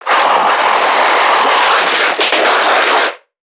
NPC_Creatures_Vocalisations_Infected [13].wav